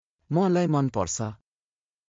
当記事で使用された音声（ネパール語および日本語）は全てGoogle翻訳　および　Microsoft TranslatorNative Speech Generation、©音読さんから引用しております。